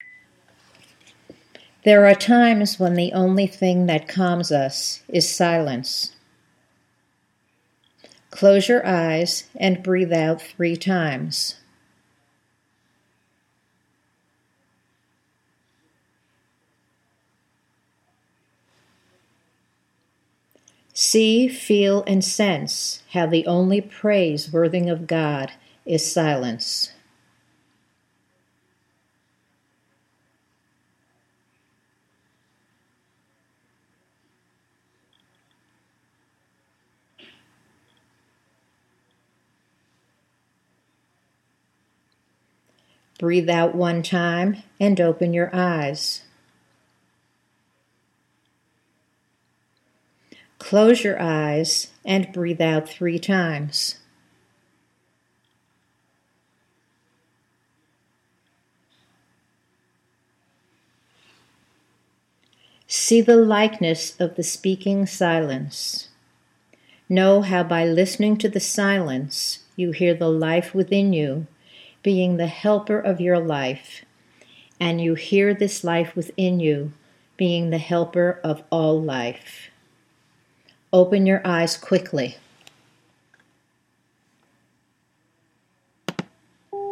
This exercise has two parts separated by several seconds on the tape.